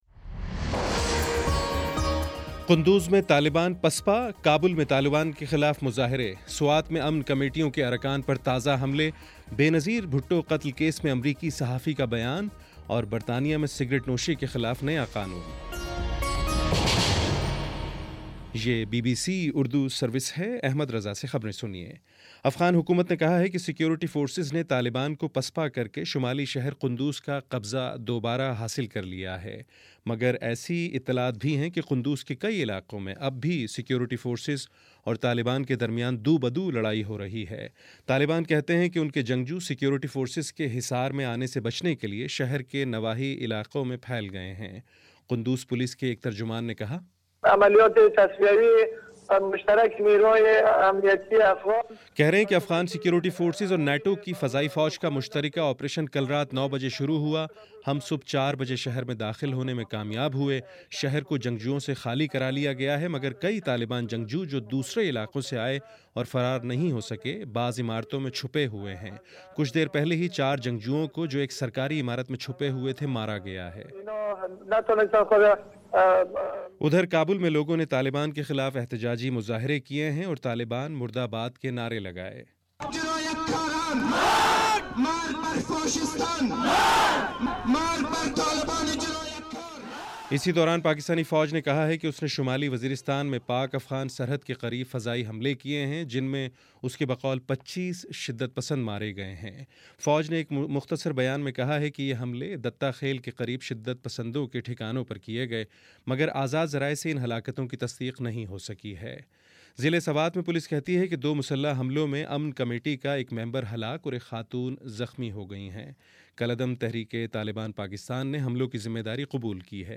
اکتوبر 1 : شام پانچ بجے کا نیوز بُلیٹن